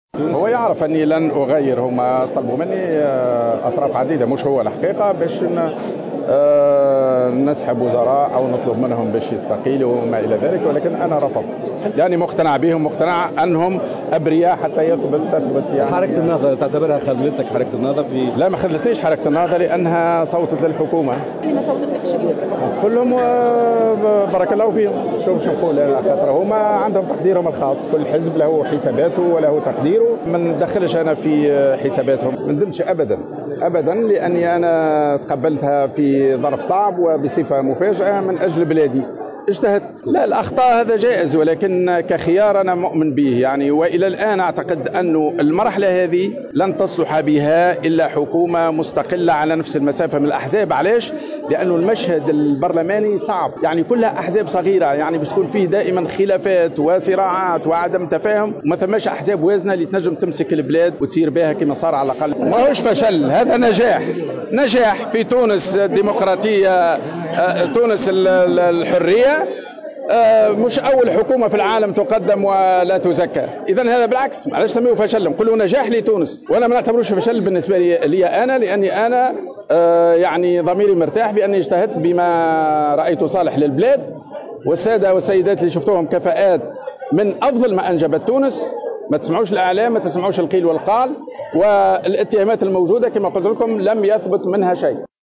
أول تصريح لحبيب الجملي بعد إسقاط حكومته المقترحة